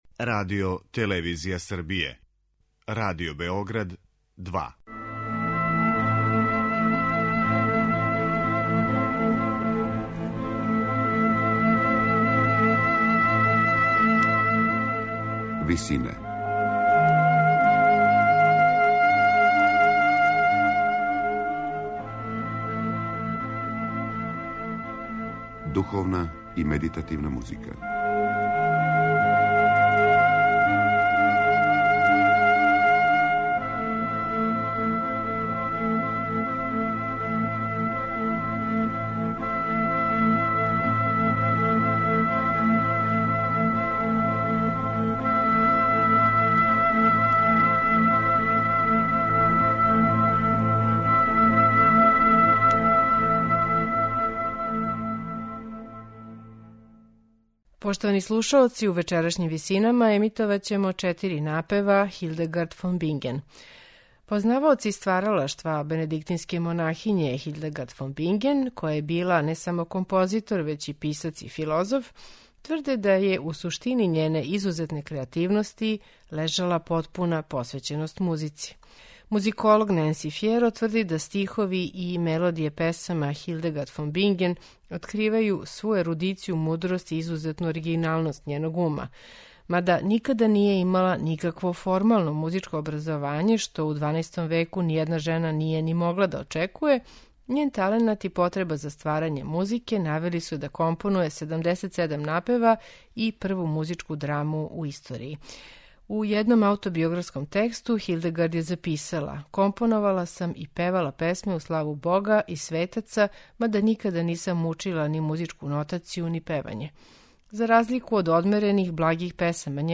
У вечерашњим Висинама , емитоваћемо четири напева Хилдегард фон Бинген у несвакидашњој интерпретацији ансамбла Мередит Монк.
медитативне и духовне композиције